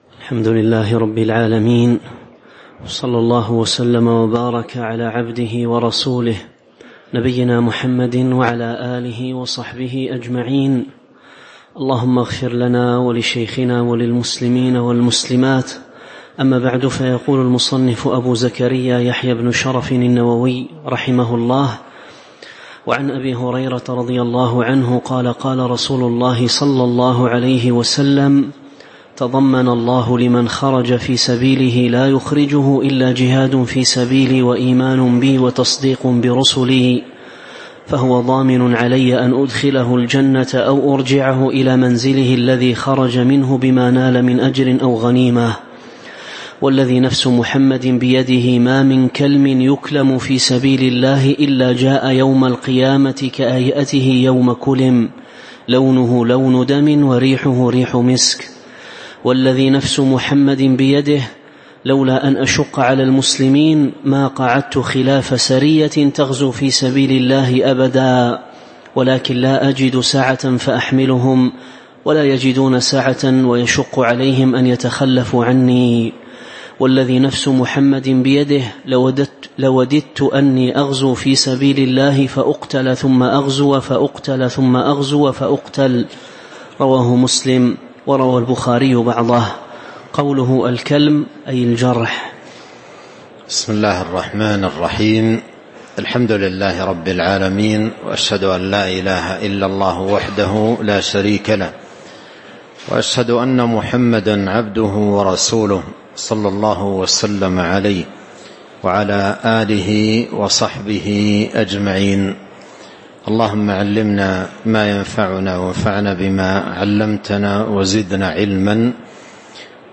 تاريخ النشر ٢٣ جمادى الآخرة ١٤٤٥ هـ المكان: المسجد النبوي الشيخ